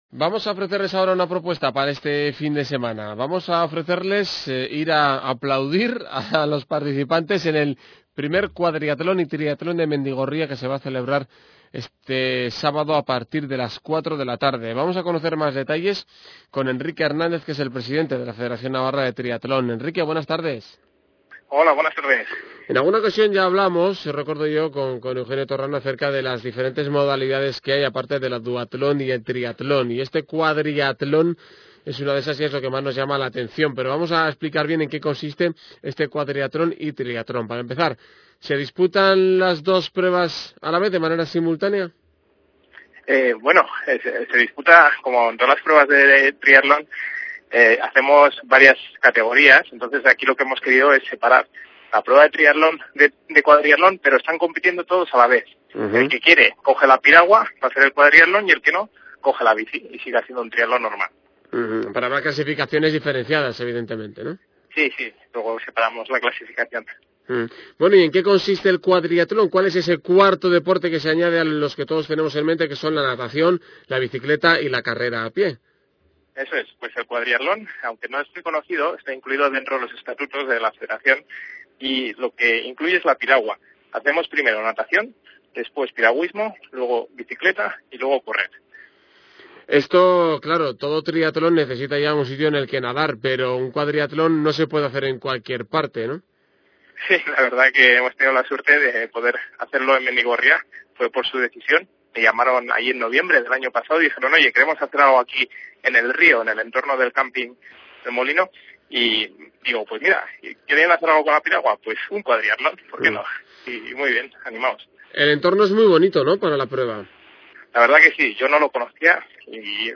Entrevista en Ondacero Navarra sobre Mendigorría
"Entrevista"